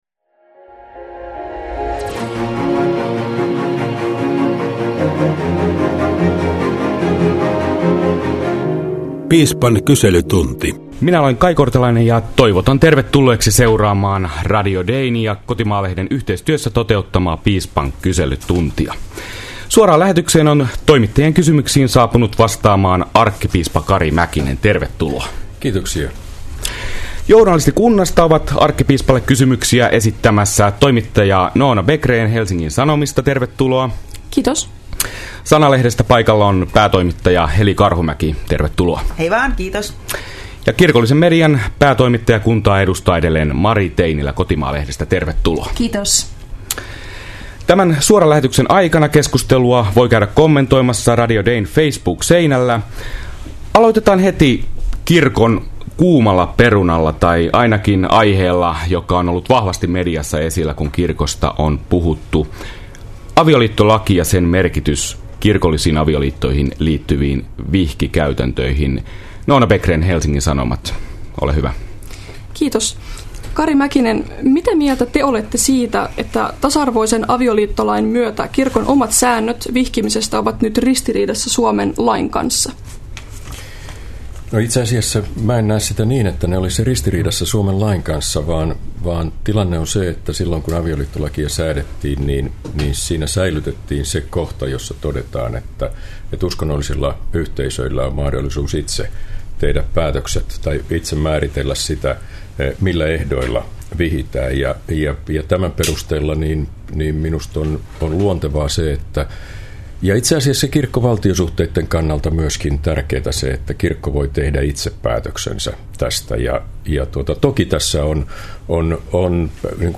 Keskiviikkona suoraan lähetykseen toimittajien kysymyksiin saapuu vastaamaan arkkipiispa Kari Mäkinen.